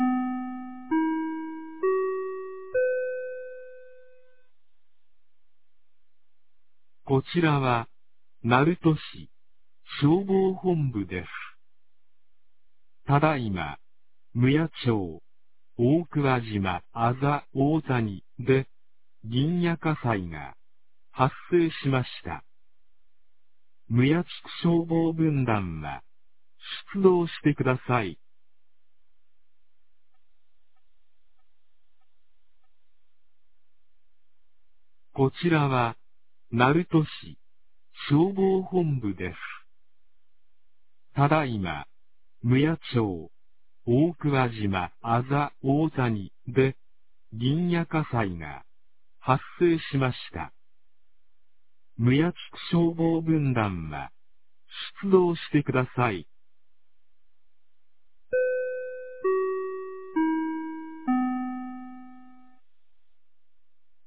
2022年03月16日 02時37分に、鳴門市より撫養町-大桑島、撫養町-北浜、撫養町-木津、撫養町-黒崎、撫養町-斎田、撫養町-立岩、撫養町-林崎、撫養町-南浜、大津町-木津野へ放送がありました。
放送音声